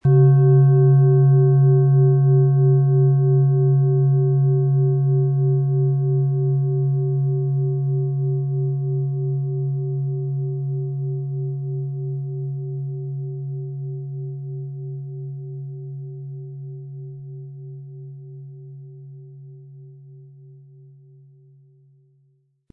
OM Ton
• Tiefster Ton: Tageston
PlanetentöneOM Ton & Tageston
MaterialBronze